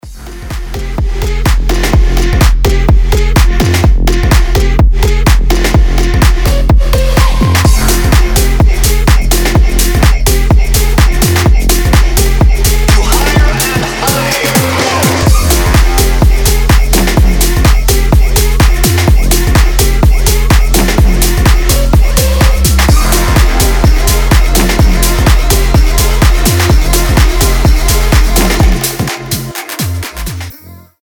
• Качество: 320, Stereo
громкие
EDM
future house
Bass House
Динамичная клубная нарезка будет крутым рингтоном